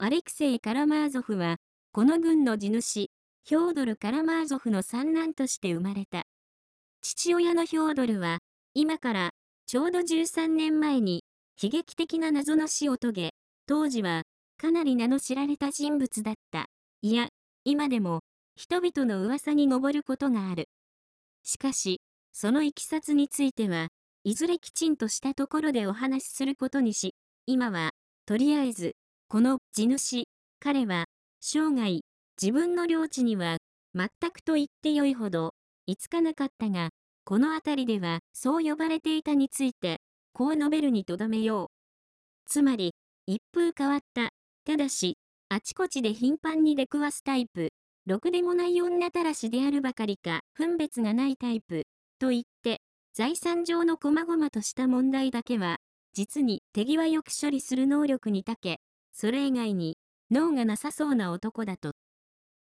電子書籍や、音声朗読機能を使ってみるのも、良さそうなので、試しに作ってみました。
男性の声